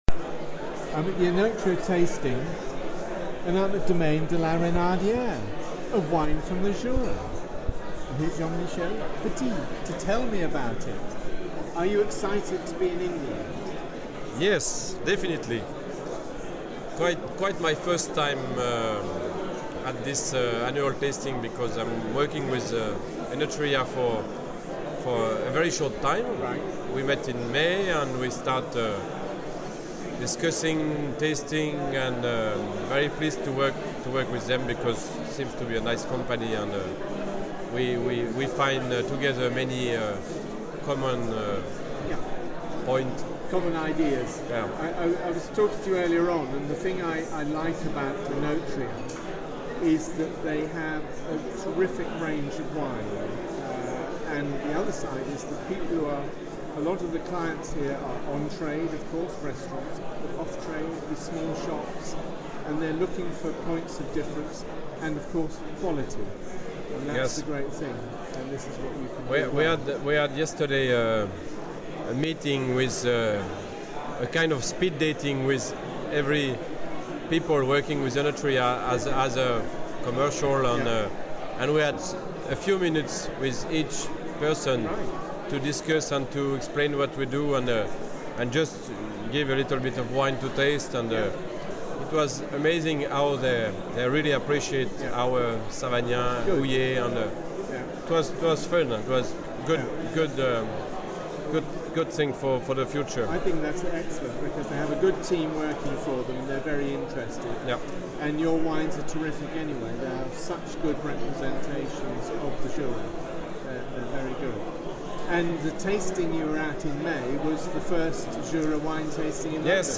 at the Enotria tasting earlier this year